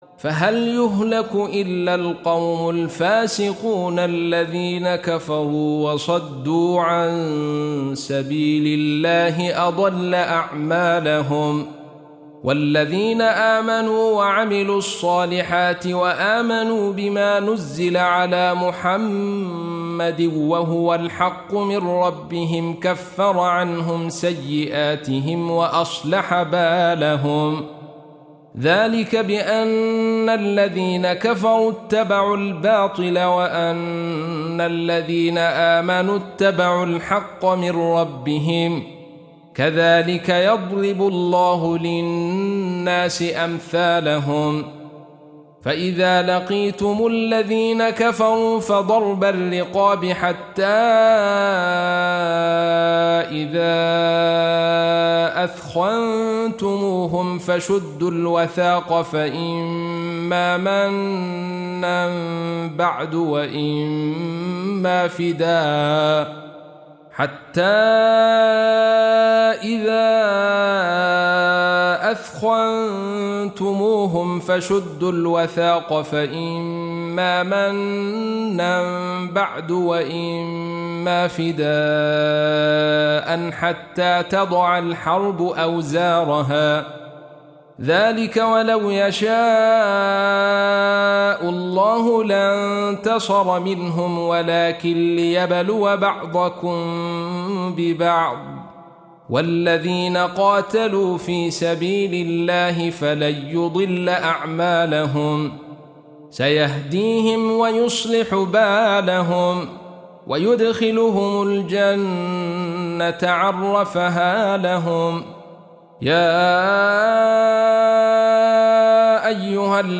47. Surah Muhammad or Al-Qit�l سورة محمد Audio Quran Tarteel Recitation
حفص عن عاصم Hafs for Assem